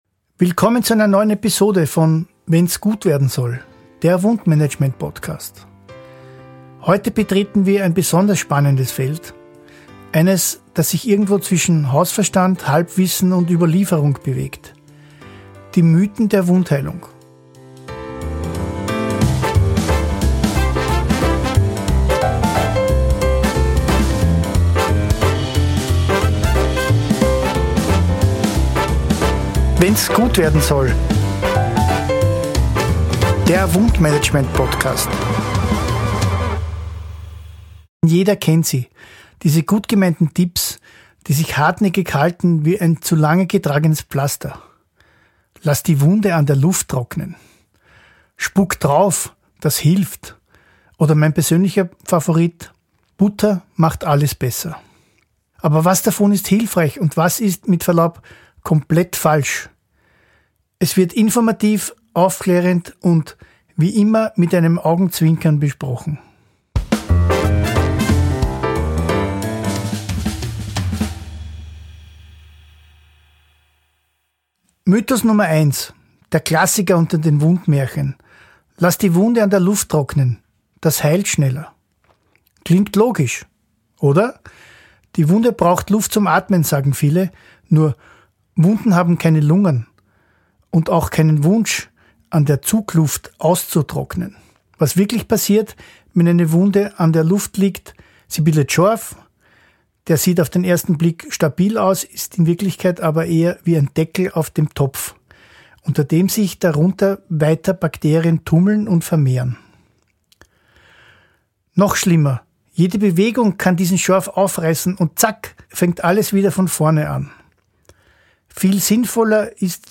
Bearbeitung & Sounddesign